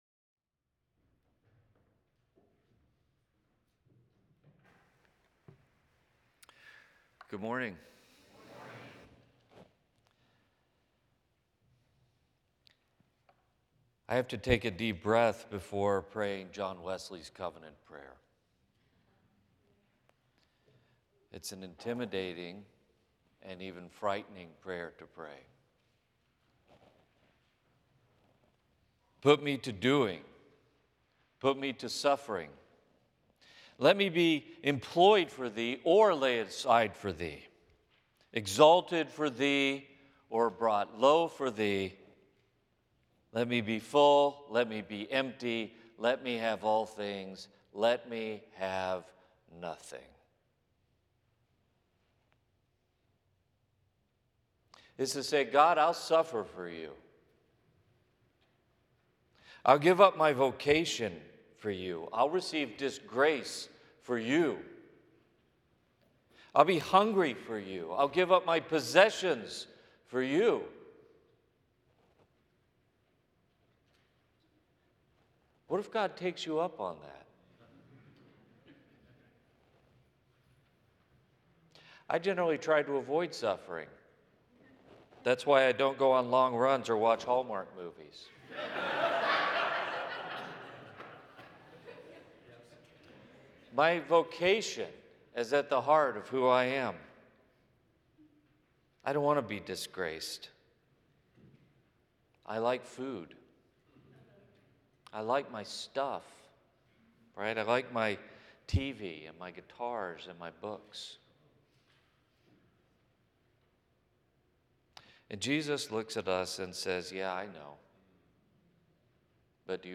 The following service took place on Wednesday, January 28, 2026.
Sermon